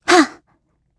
Gremory-Vox_Attack4_kr.wav